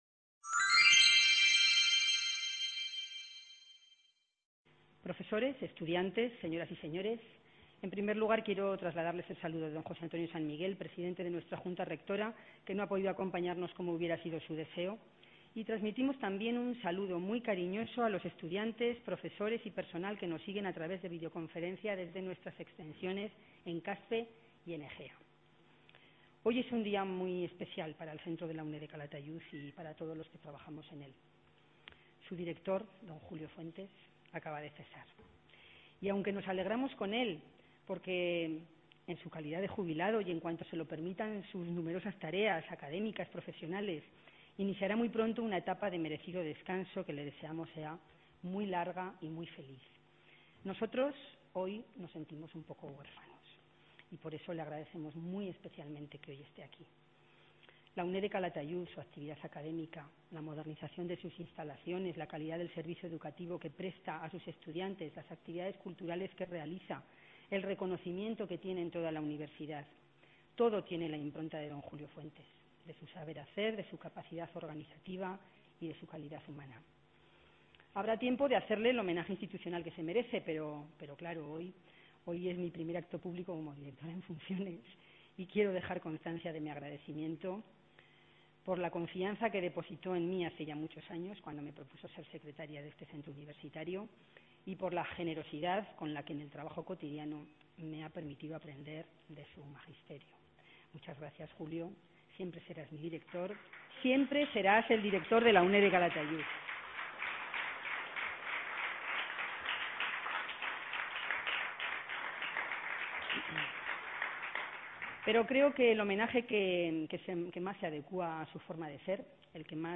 Conferencia: Mujeres bajo sospecha: Memoria y…
Celebración del DÍA DE LA UNED en el Centro de Calatayud